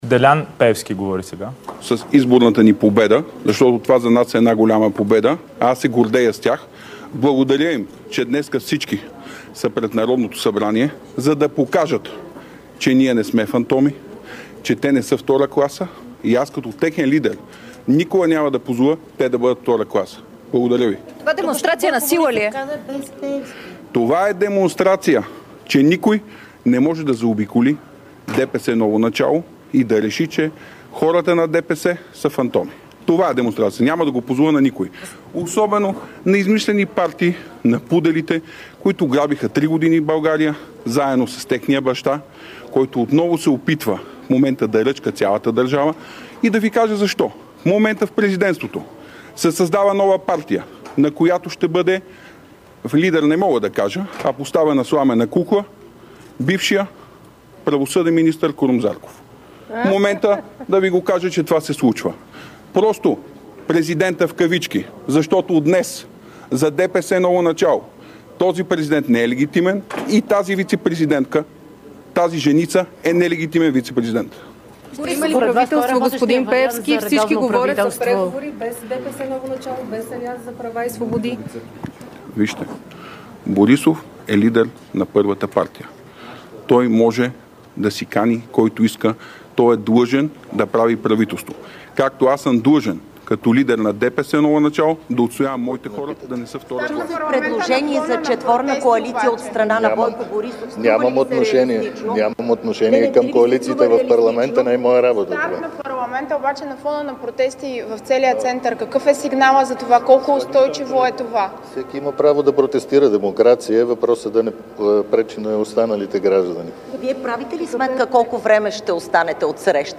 9.45 - Брифинг на съпредседателя на ДПС Делян Пеевски и на служебния премиер Димитър Главчев. - директно от мястото на събитието (Народното събрание)